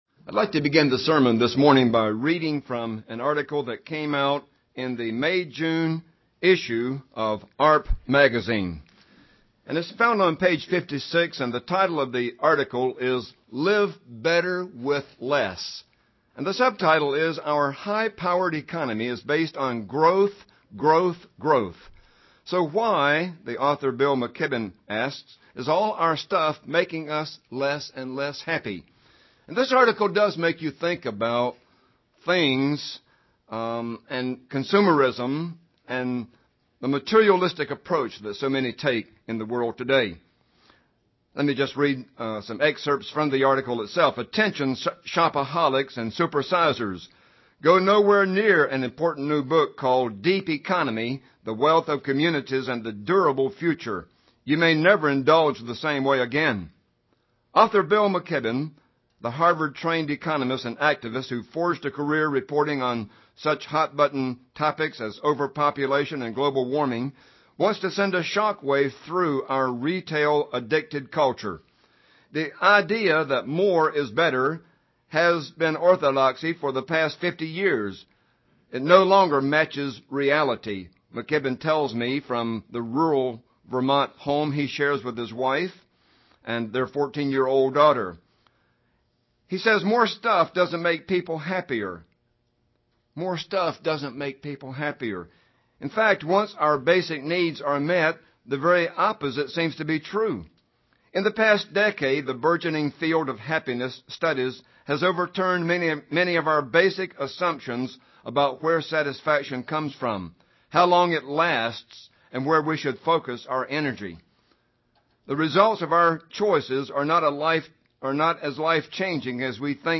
Looking into the Word of God for inspiration towards living a happier life happiness UCG Sermon Transcript This transcript was generated by AI and may contain errors.